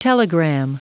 Prononciation du mot telegram en anglais (fichier audio)